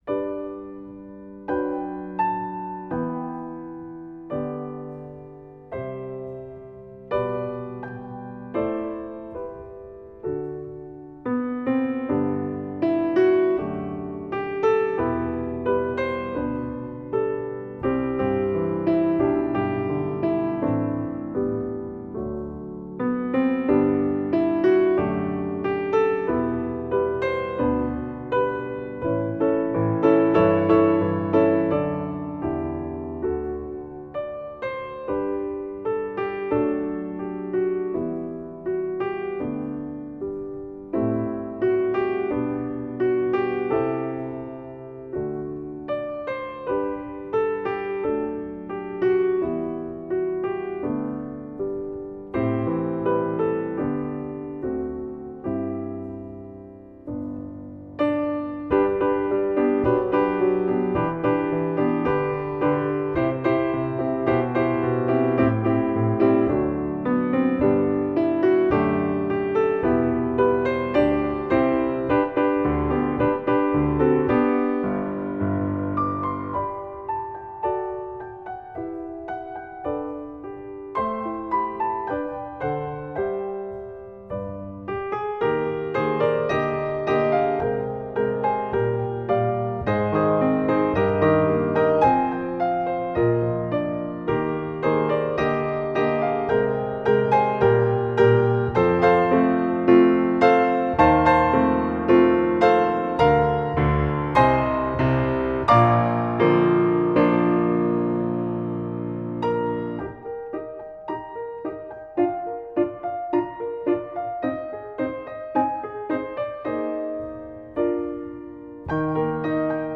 Voicing: Piano Solo Collection